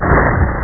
Amiga 8-bit Sampled Voice
1 channel
landingthump.mp3